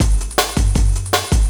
06 LOOP01 -L.wav